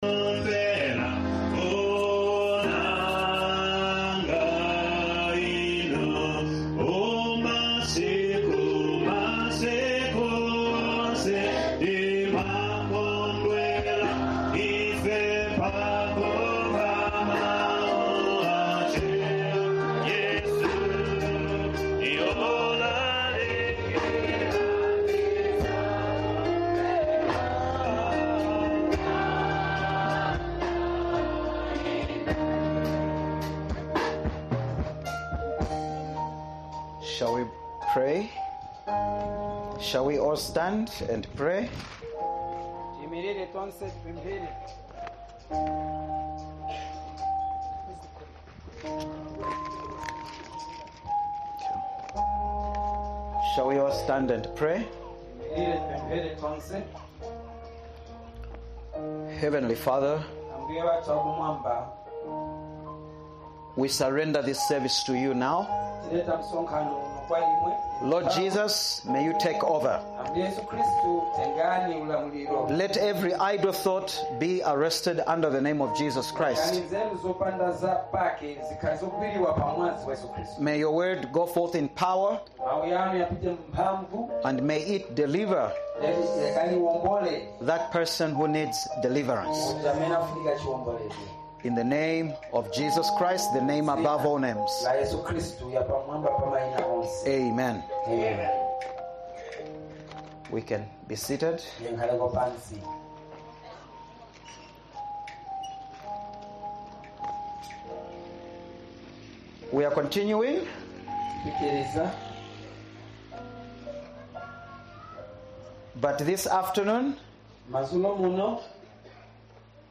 Mtenguleni Crusade - Second Service | August 30, 2025